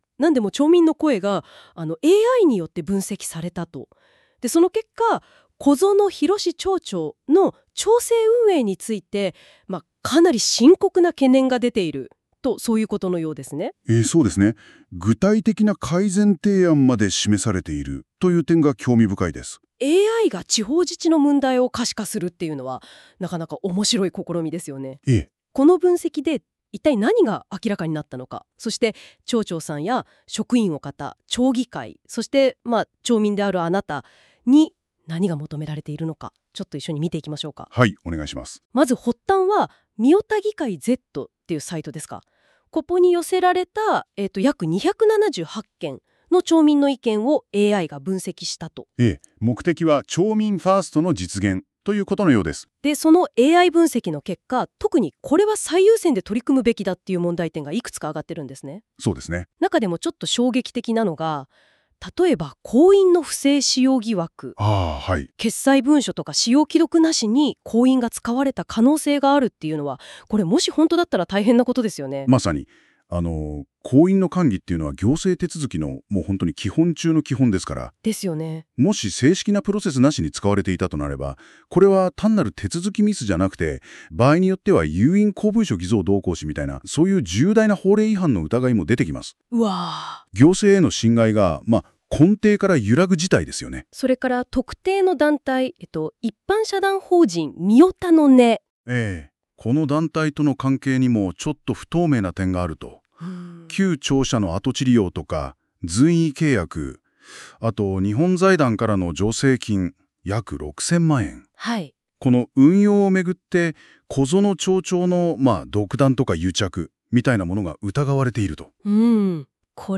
音声解説
AI生成なので固有名詞などの誤読がありますがご了承ください。